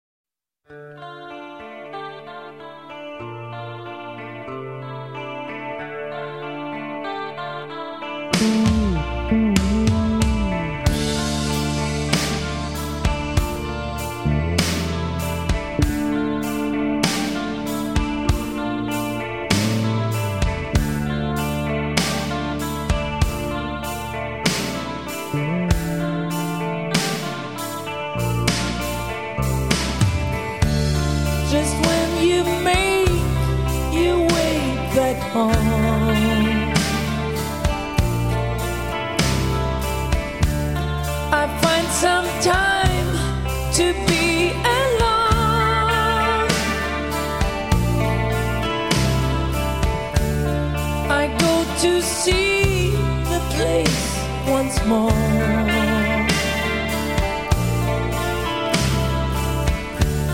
Рок
Самые известные баллады